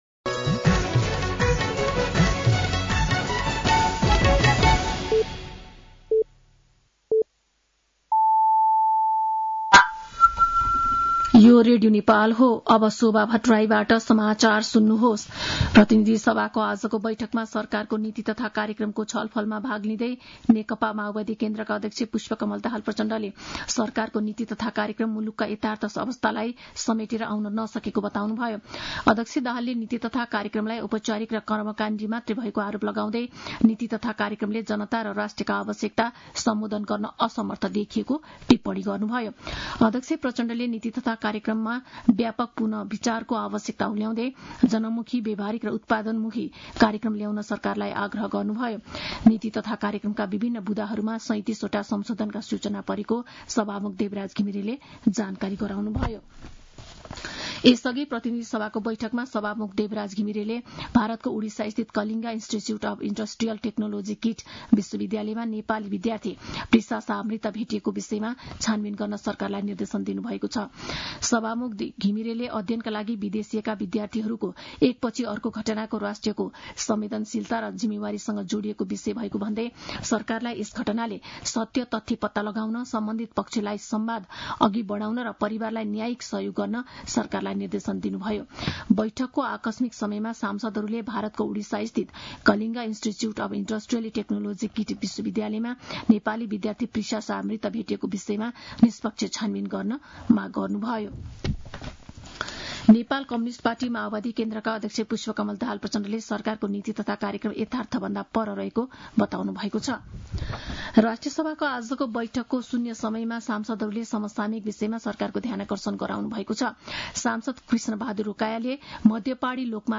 साँझ ५ बजेको नेपाली समाचार : २२ वैशाख , २०८२
5-pm-news-1.mp3